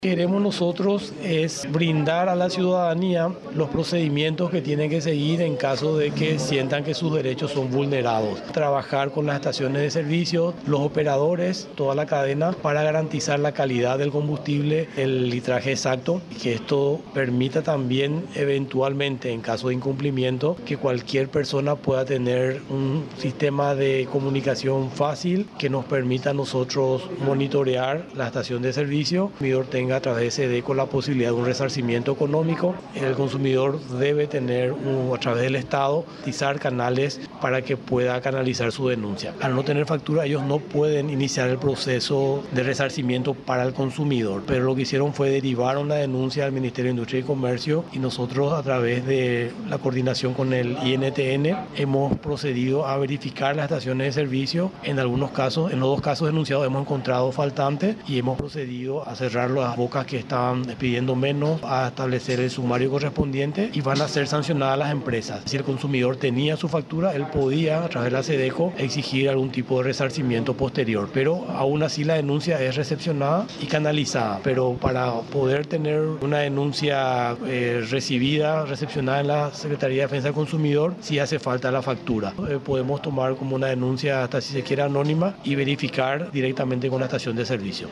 30-VICEMINISTRO-PEDRO-MANCUELLO.mp3